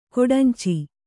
♪ koḍanci